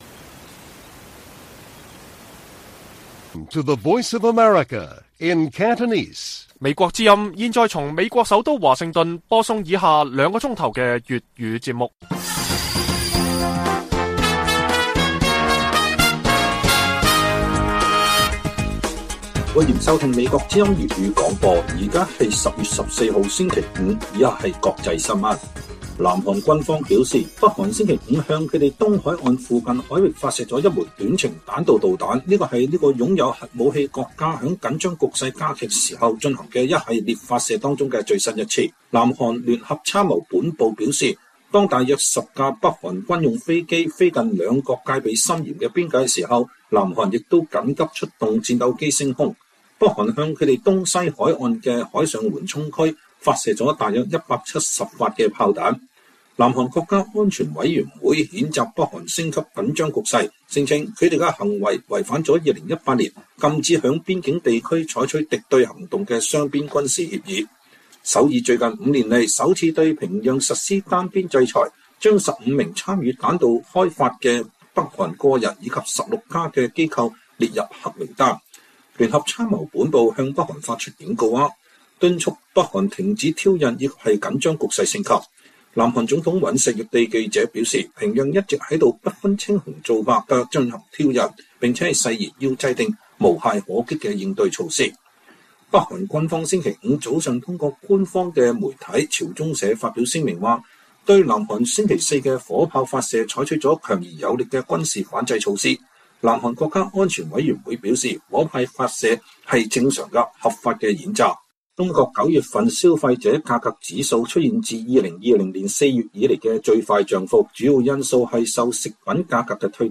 粵語新聞 晚上9-10點: 北韓發射導彈, 軍機在兩韓邊界飛行